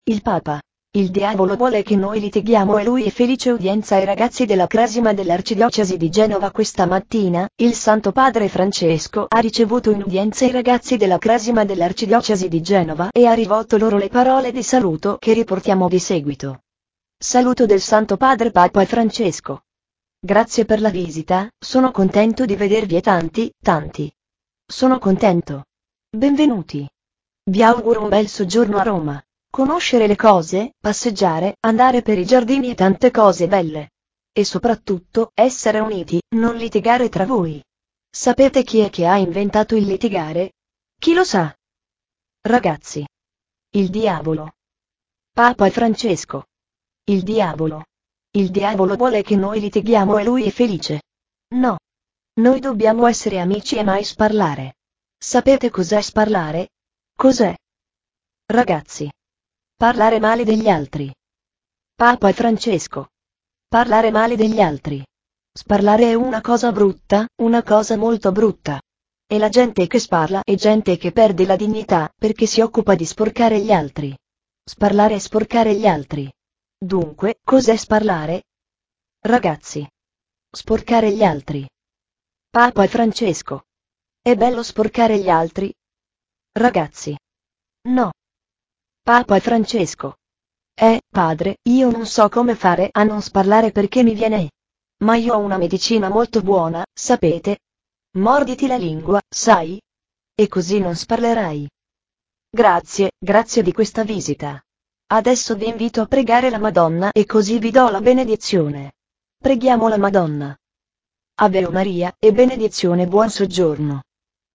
Udienza ai ragazzi della Cresima dell’Arcidiocesi di Genova
Questa mattina, il Santo Padre Francesco ha ricevuto in Udienza i ragazzi della Cresima dell’Arcidiocesi di Genova e ha rivolto loro le parole di saluto che riportiamo di seguito:
Saluto del Santo Padre